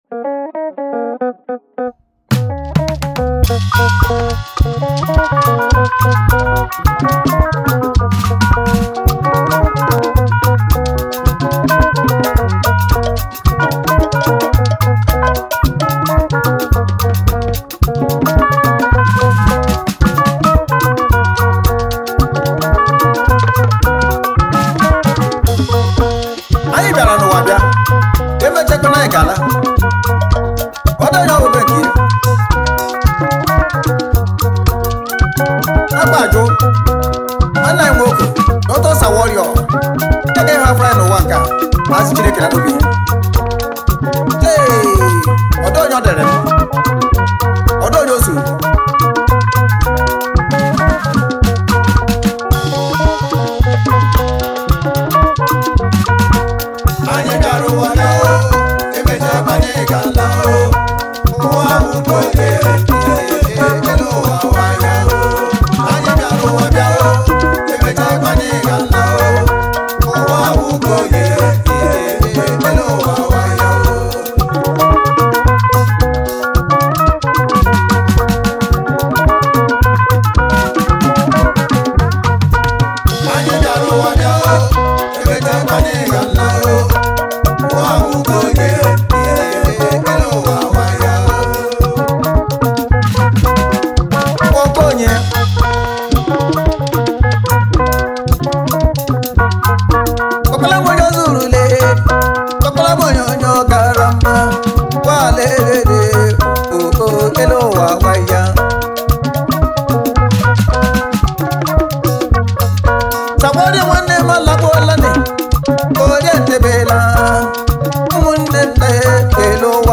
Highlife Music
deep sensational highlife single